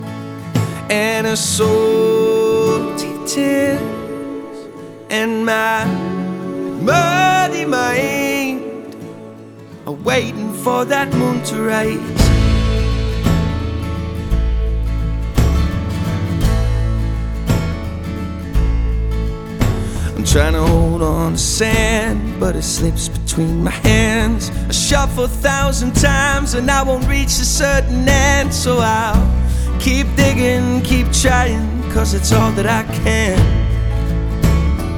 Жанр: Альтернатива / Фолк-рок